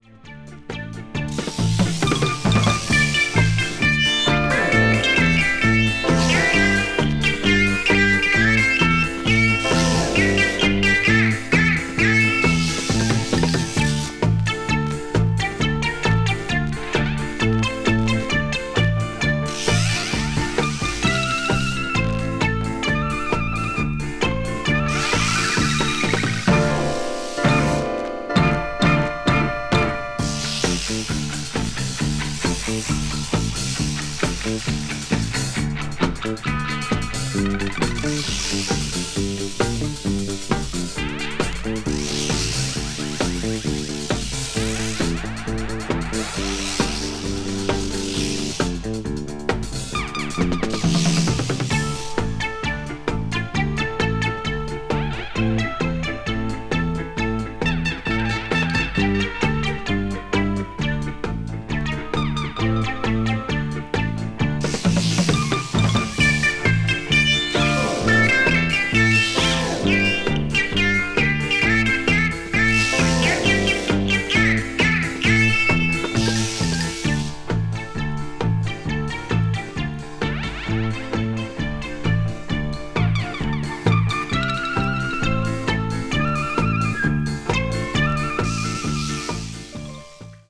media : EX/EX(わずかにチリノイズが入る箇所あり)
east asia   instrumental   minyo   taiwan   world music